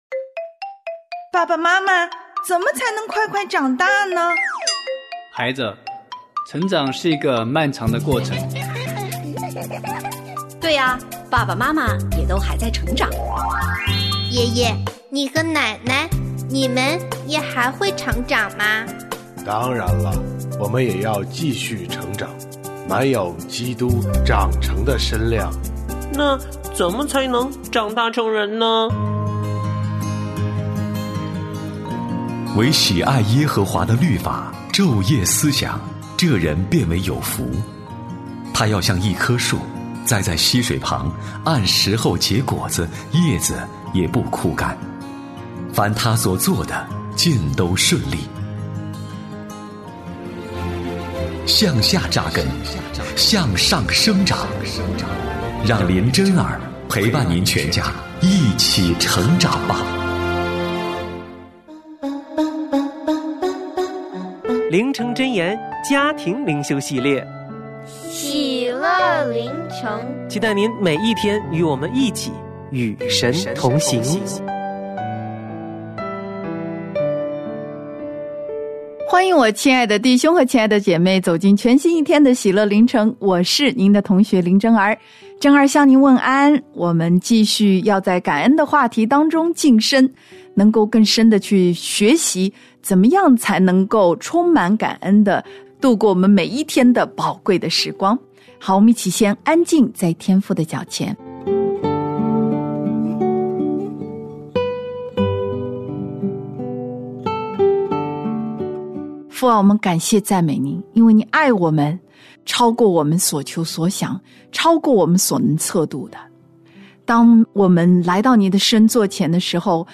我家剧场：圣经广播剧（141）摩押背叛以色列王；以利沙的教训与预言